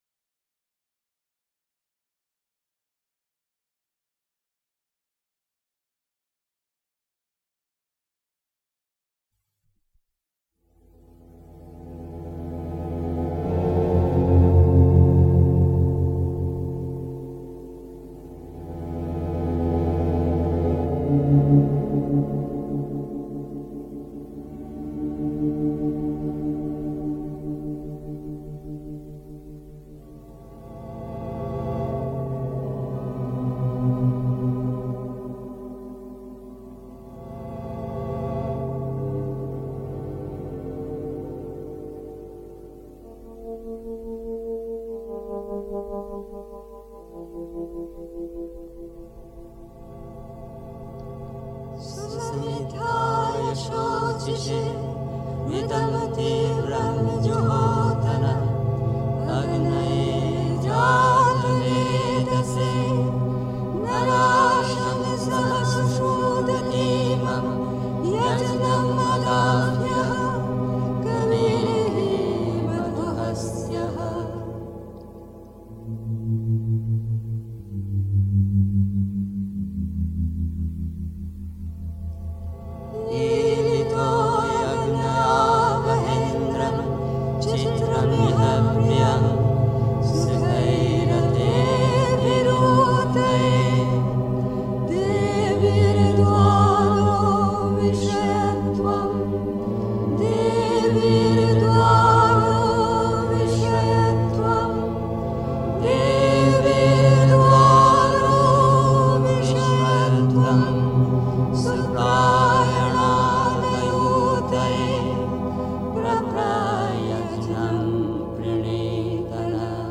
Ratschläge für den, der entschlossen ist, die Entdeckung der Gottheit im Innern zu unternehmen (Die Mutter, CWM, Vol. 12, pp. 32-35) 3. Zwölf Minuten Stille.